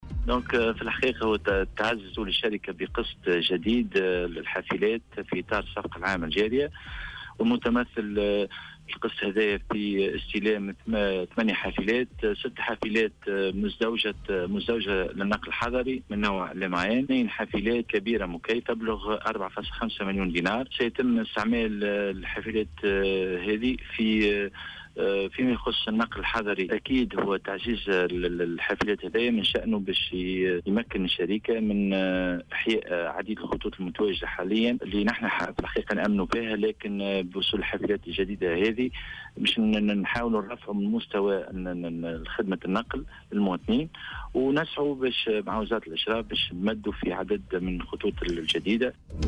وأضاف في تصريح لمراسل "الجوهرة أف أم" أن الاقتناءات شملت 6 حافلات مزدوجة للنقل الحضري وحافلتين مكيفة بقيمة جملية قدّرت بـ 4.5 مليون دينار.